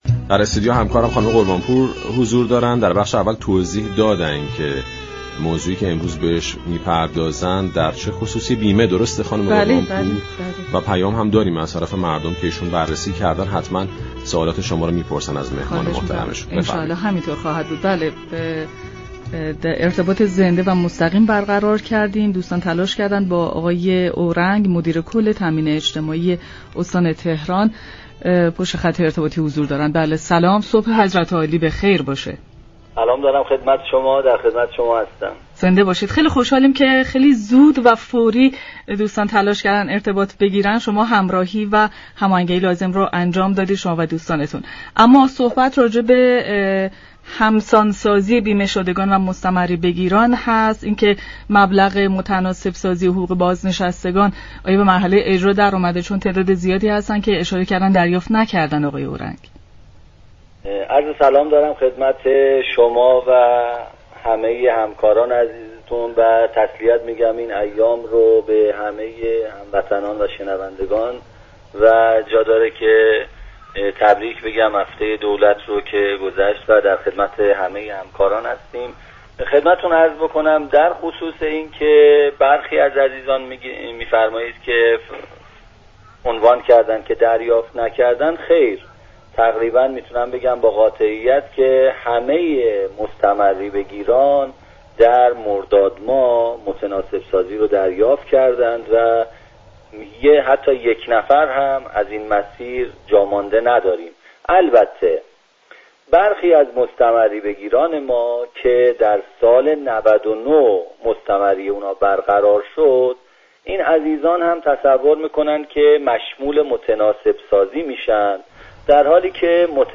عباس اورنگ، مدیر كل تامین اجتماعی شهرستان های استان تهران در خصوص طرح همسان سازی بیمه شدگان و مستمری بگیران و عدم اجرای این طرح با پارك شهر رادیو تهران به گفتگو كرد.